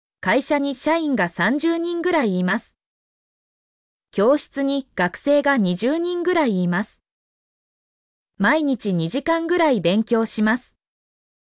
mp3-output-ttsfreedotcom-38_NU0MxBWl.mp3